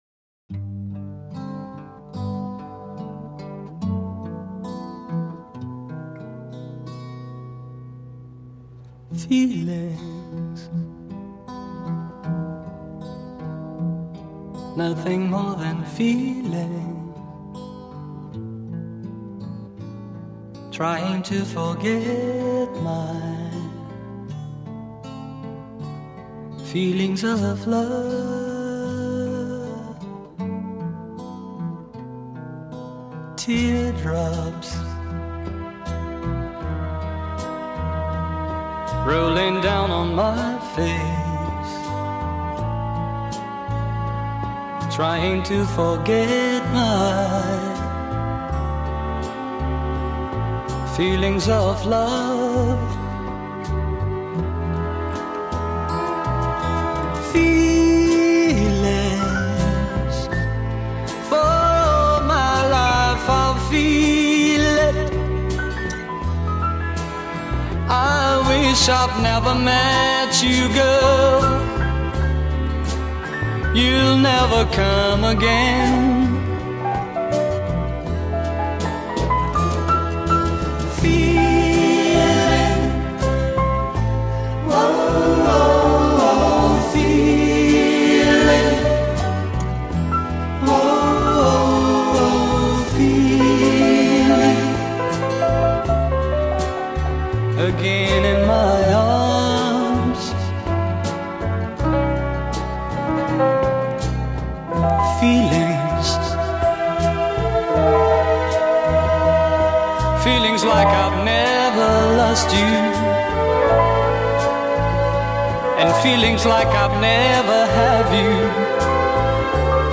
джаз, поп-музыка